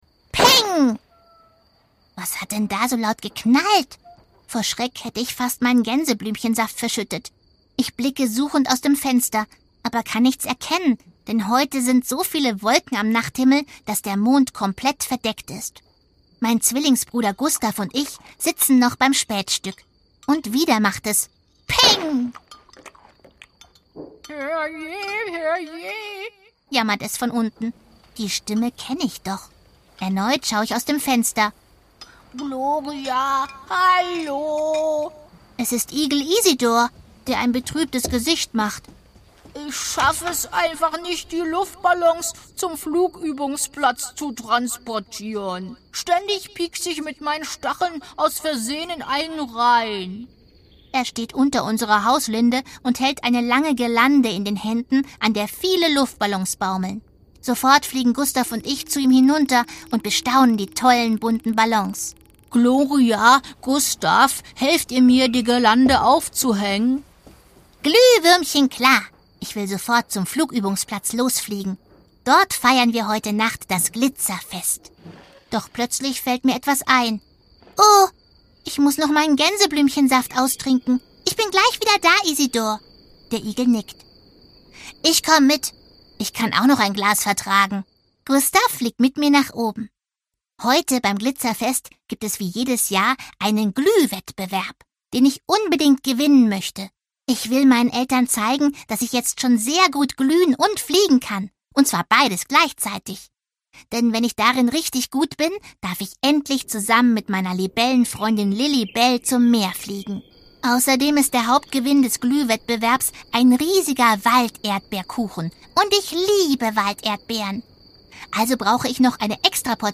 Sabine Bohlmann (Sprecher)
2017 | Ungekürzte Lesung, Inszenierte Lesung mit Musik
Vielstimmig gelesen und gesungen von Sabine Bohlmann